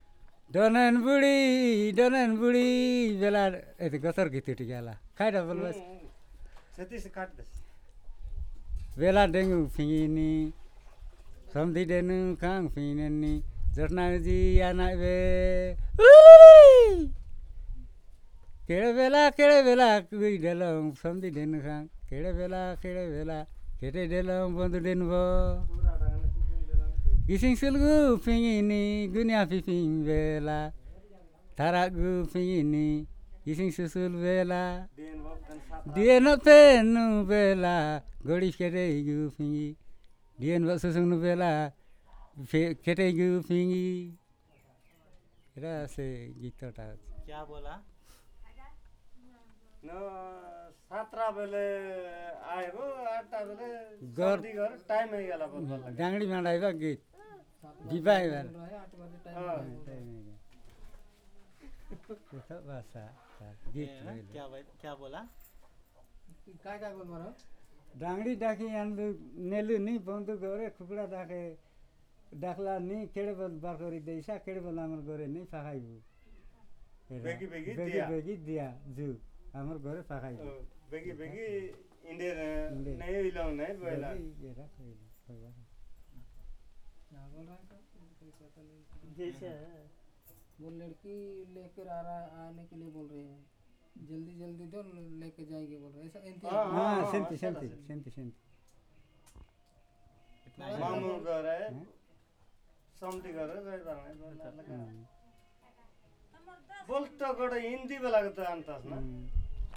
Perfomance of Goter song